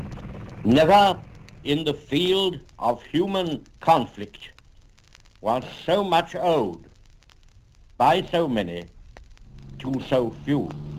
Historic Broadcasts
Churchill_So much...so few.wav Churchill's very famous often quoted speech about how so much is owed by so many to so few. He is referring to the RAF pilots who saved Britain from invasion in the Battle of Britain.